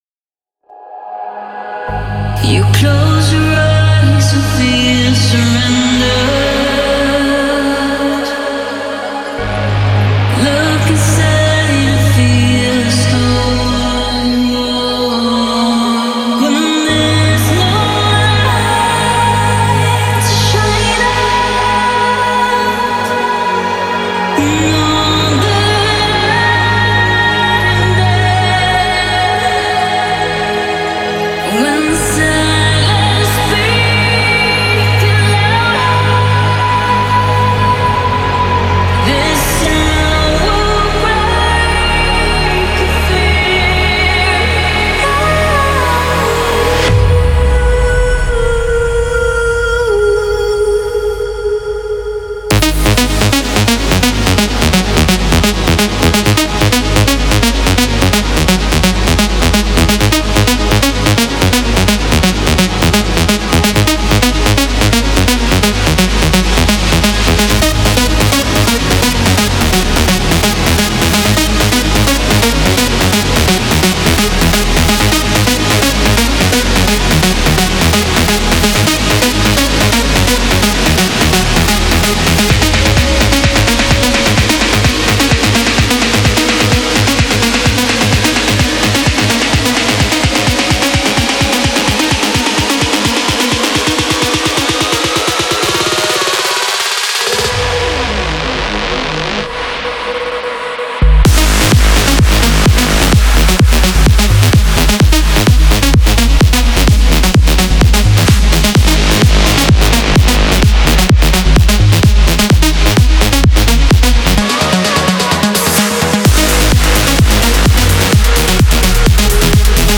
энергичная EDM-композиция